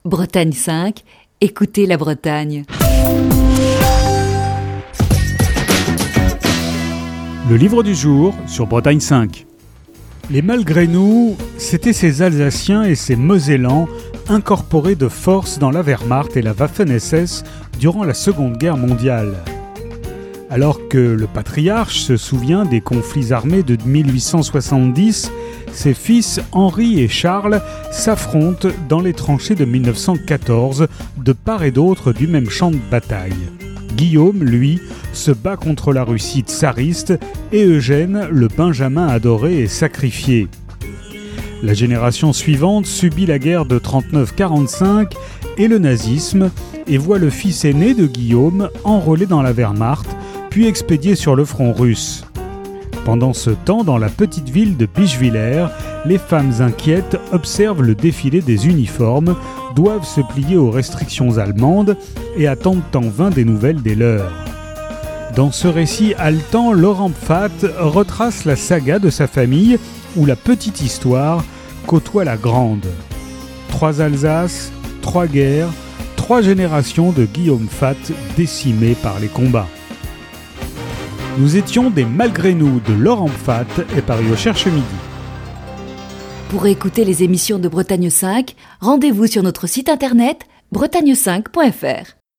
Chronique du 30 juin 2020.